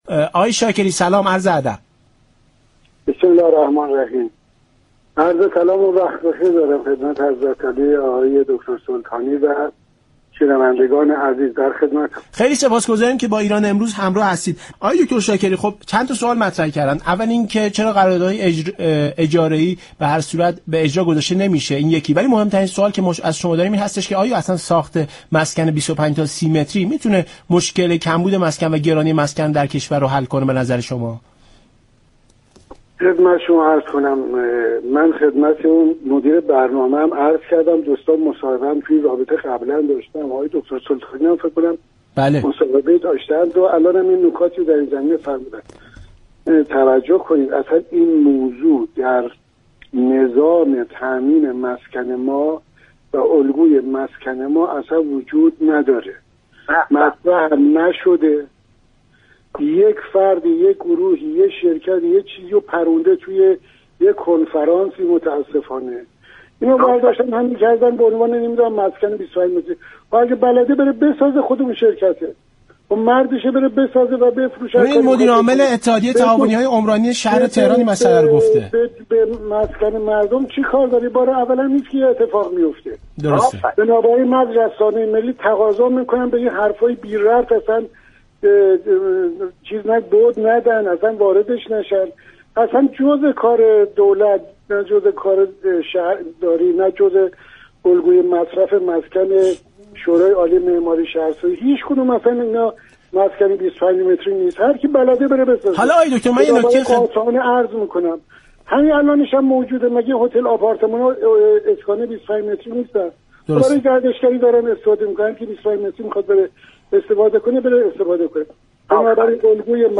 به گزارش شبكه رادیویی ایران، اقبال شاكری عضو كمیسیون عمران مجلس در برنامه ایران امروز به طرح آپارتمان 25 متری پرداخت گفت: ساخت مسكن 25 متری در نظام تامین مسكن كشور وجود خارجی ندارد و این گفته تنها از سوی گروه و فردی در یك همایش و كنفرانس بیان شده است.